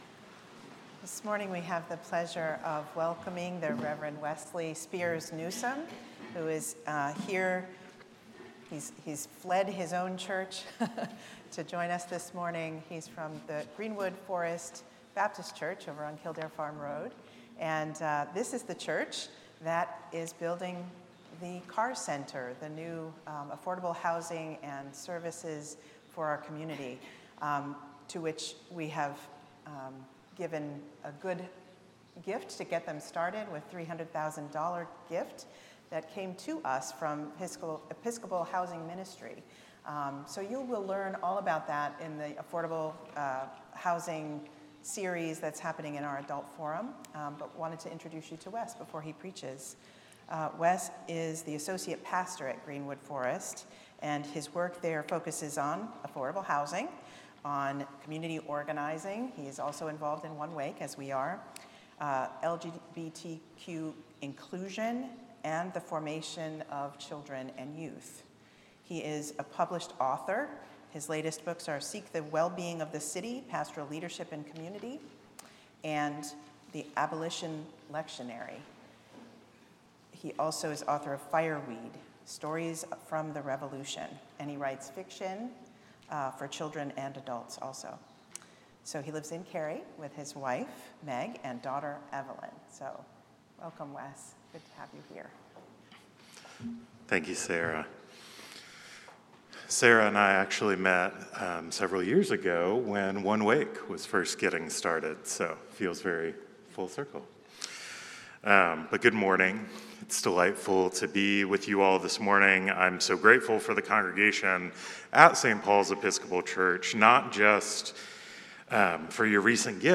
St-Pauls-HEII-9a-Homily-21SEP25.mp3